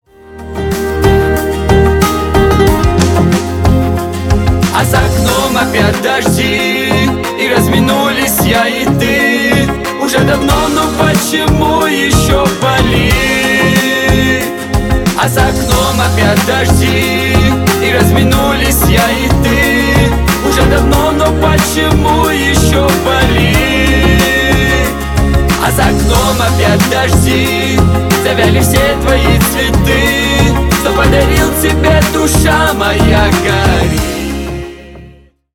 Поп Музыка
грустные
кавказские